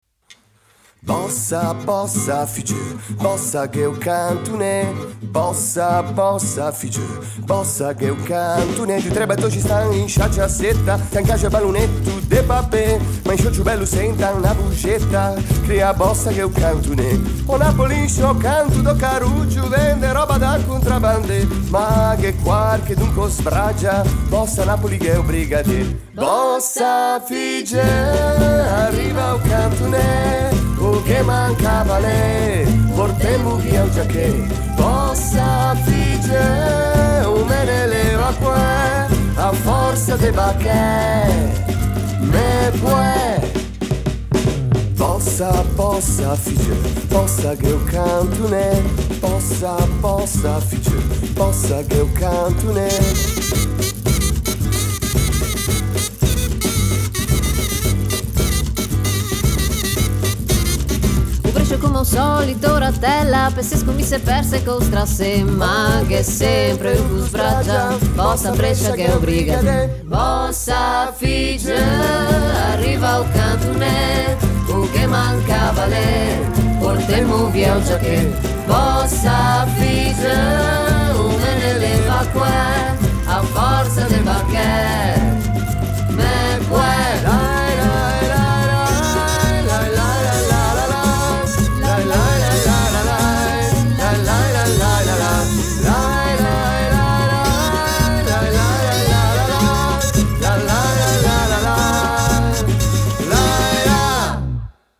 Chitarre
Basso
Percussioni